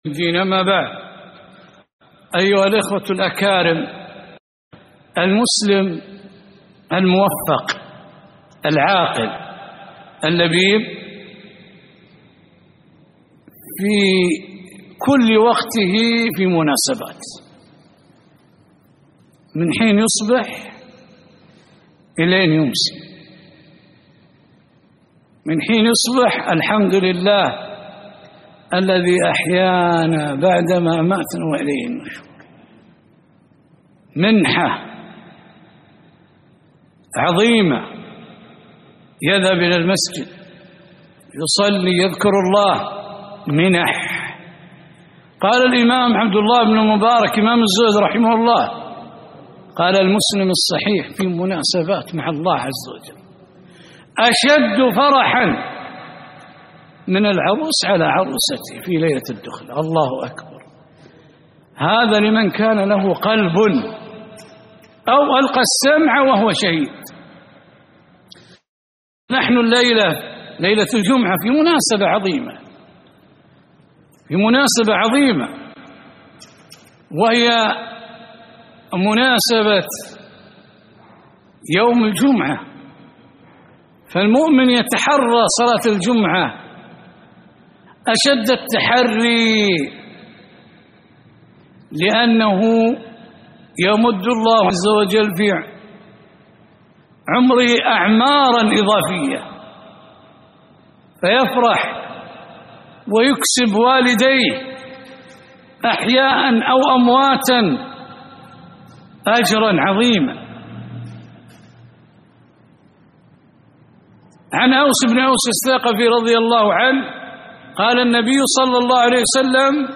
كلمة - فضائل يوم الجمعة